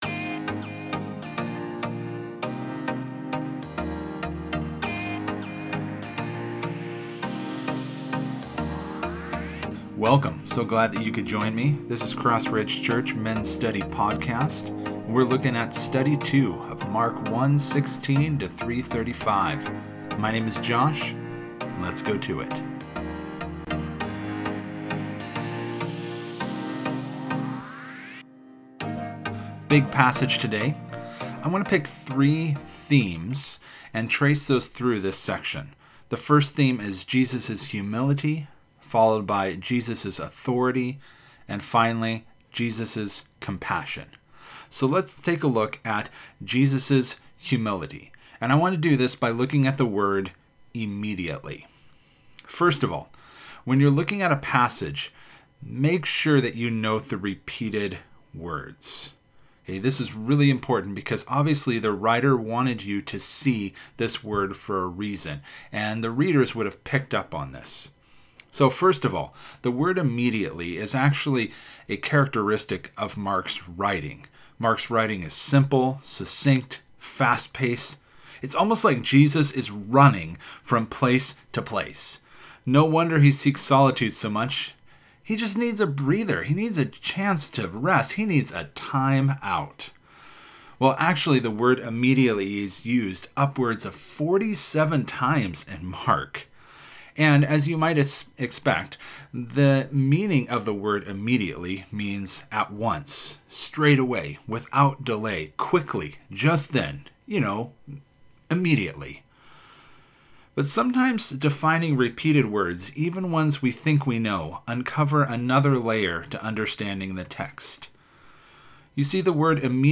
Teaching podcast for Study 2 (Mark 1:16-3:35) of the Men's Study of the Gospel of Mark.